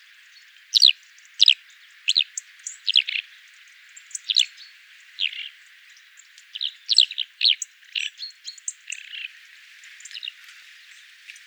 Alauda arvensis - Skylark - Allodola
DATE/TIME: 29/october/2019 (8 a.m.) - IDENTIFICATION AND BEHAVIOUR: One bird flies off from a small meadow with wild grass between a rocky coast and a village. - POSITION: Pomonte village, Isola d'Elba, Livorno, LAT.N 42°44'/LONG.E 10°07' - ALTITUDE: 0 m. - VOCALIZATION TYPE: flight calls - SEX/AGE: unknown - COMMENT: There are at least two different call types in the audio sample. These calls are quite common for the species. Background: Robin and Chaffinch. - MIC: (P)